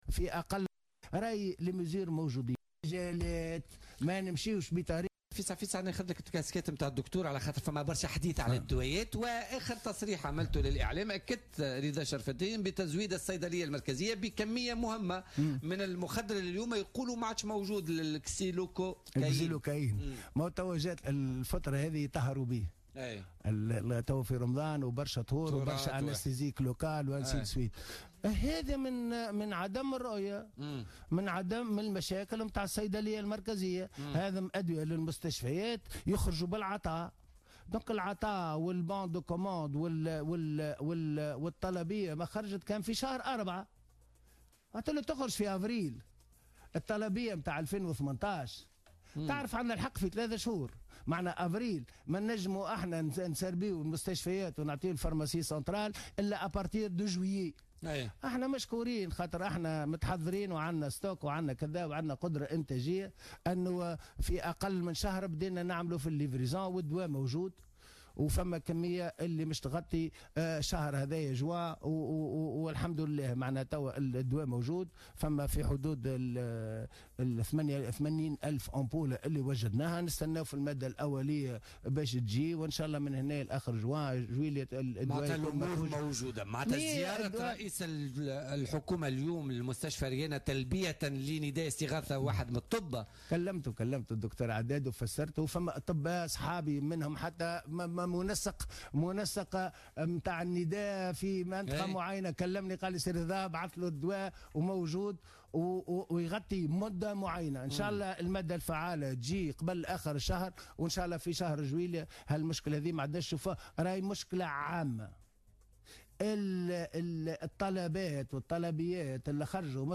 قال النائب رضا شرف الدين ضيف بولتيكا اليوم الإثنين إن مخبر انيماد تمكن من حل المشكل الذي كان أثاره طبيب بمستشفى عبد الرحمان مامي بأريانة والمتعلق بفقدان مخدر كاسيلوكايين" .